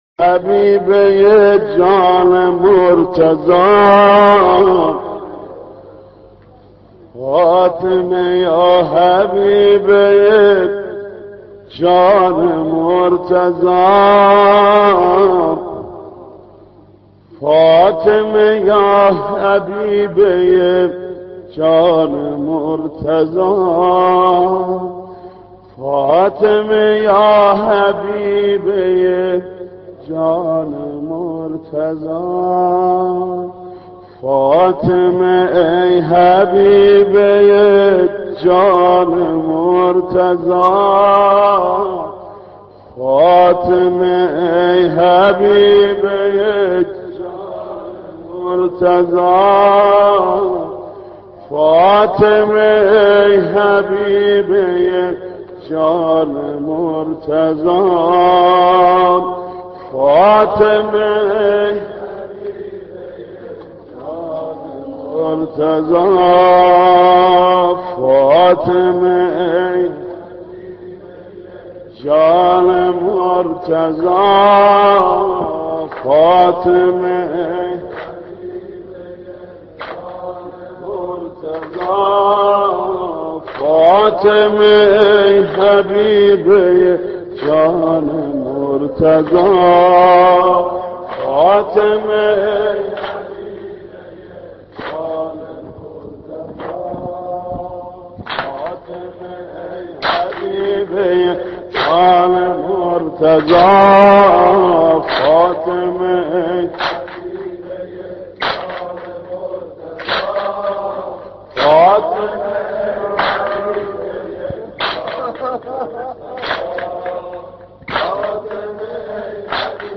دانلود مداحی فاطمه ای حبیبه جان مرتضی - دانلود ریمیکس و آهنگ جدید
نوحه‌خوانی حاج محمود کریمی به مناسبت شهادت حضرت فاطمه(س) (16:07)